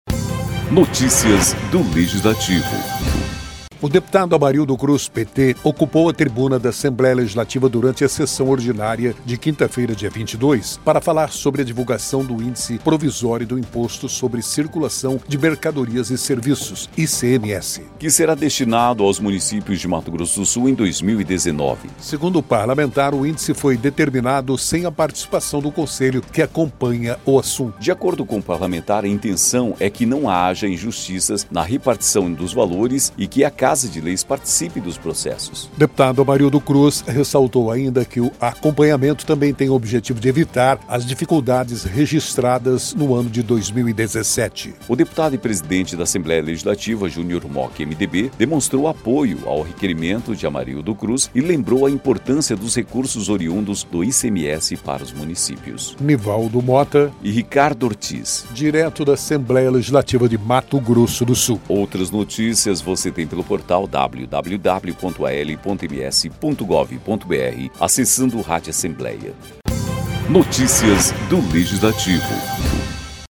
O deputado Amarildo Cruz (PT) ocupou a tribuna da Assembleia Legislativa durante a sessão ordinária desta quinta-feira (22) para falar sobre a divulgação do índice provisório do Imposto sobre Circulação de Mercadorias e Serviços (ICMS) que será destinado aos municípios de Mato Grosso do Sul em 2019.